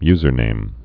(yzər-nām)